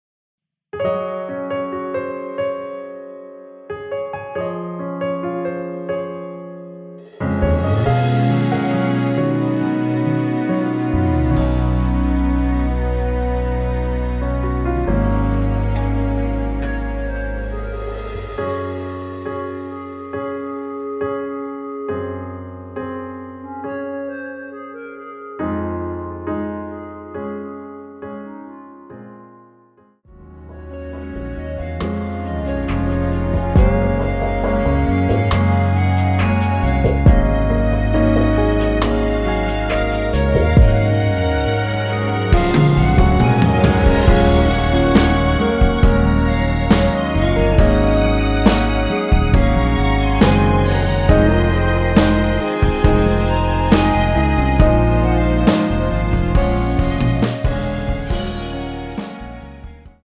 원키에서(-2)내린 멜로디 포함된 MR입니다.(미리듣기 확인)
Db
앞부분30초, 뒷부분30초씩 편집해서 올려 드리고 있습니다.